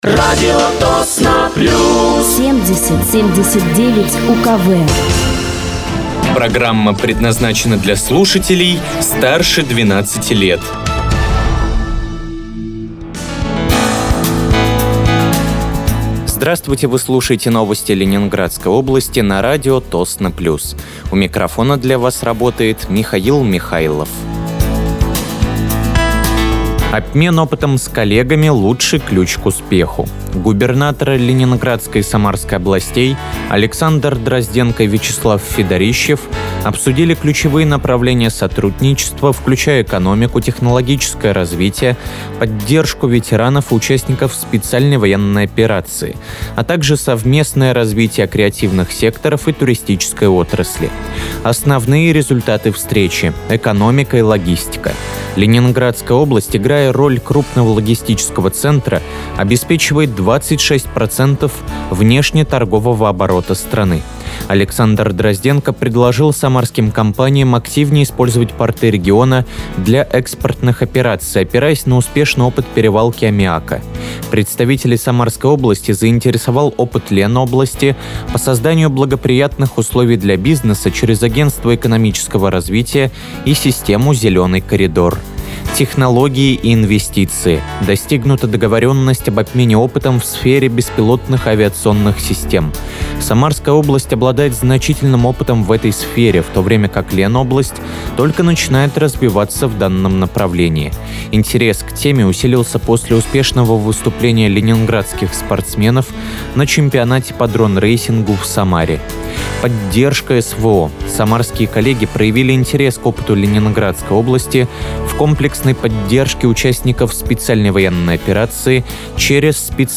Выпуск новостей Ленинградской области от 19.11.2025
Вы слушаете новости Ленинградской области на радиоканале «Радио Тосно плюс».